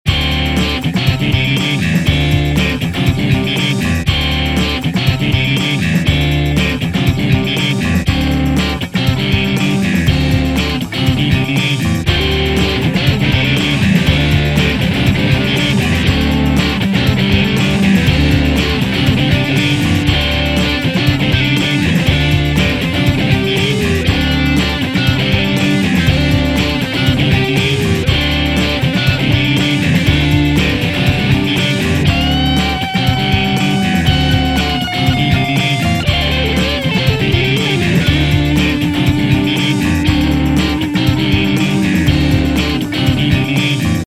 Voila j'ai enregistrer un ptit truc avec la variax.
C'est enregistré et mixé a la va vite, et c'est pas non plus en place a cause d'un monitoring foireu ( jentend pas ce ke je joue, et j'entend pas le "playback". aucune correction d'égalisation des instru au mixage.
Guitare rythmik : Stratocaster - Micro chevalet - Ampli : Marshall "Plexi" SuperLead
-Guitare Pseudo Solo : Les paul - 2 Micro - Marshall JCM800
- Puis la basse pour info : Precision basse - Ampli BassMan
La compression MP3 a pas fait du bien au son.
Variax+Pod2.MP3